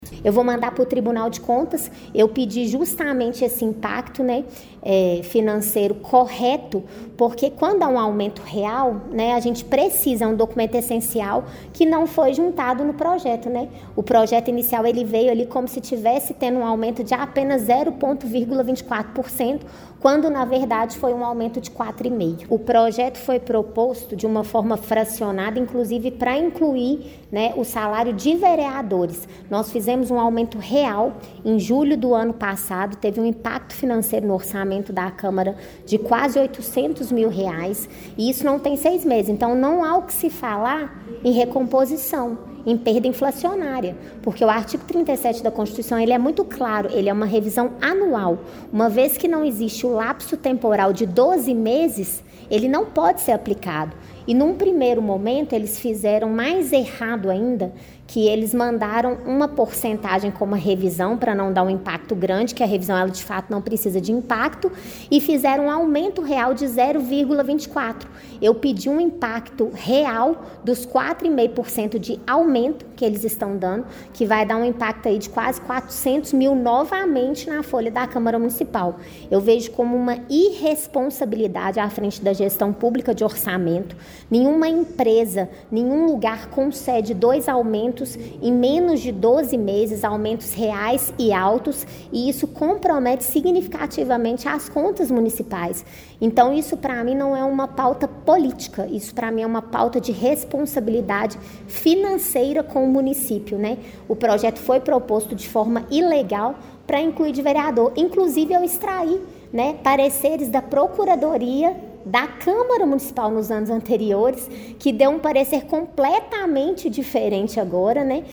Após a votação, a vereadora reforçou seu entendimento em entrevista coletiva e afirmou que formalizará denúncia junto ao Tribunal de Contas do Estado de Minas Gerais (TCE-MG), buscando a análise técnica da legalidade do projeto aprovado: